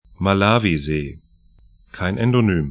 Pronunciation
Malawisee ma'la:vize: Lago Niassa 'la:gu 'nĭasa pt See / lake 12°24'N, 34°36'E